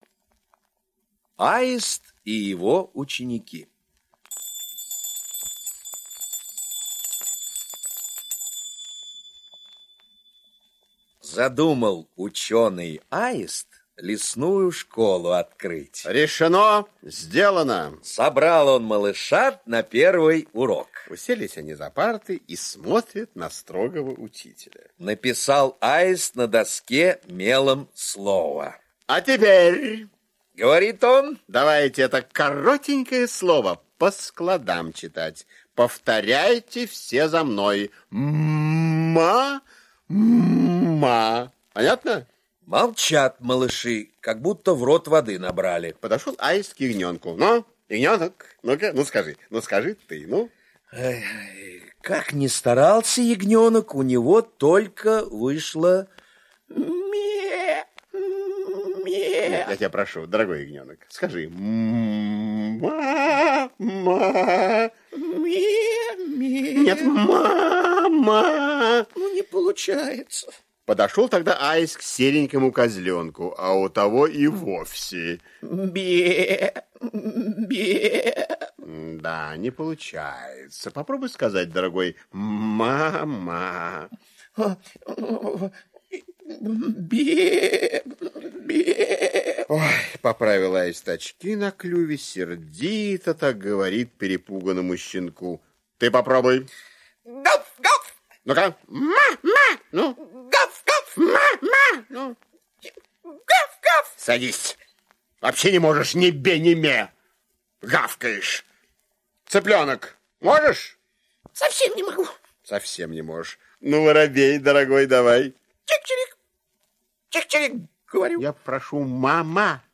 Аудиосказка – Аист и его ученики